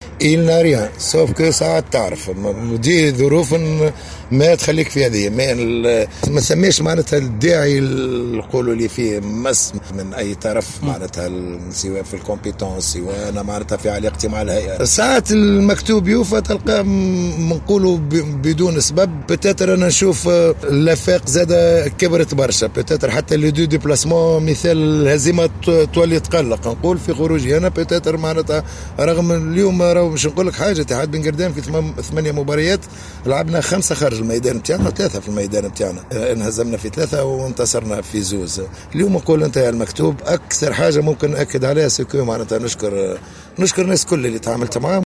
في تصريح لجوهرة أف أم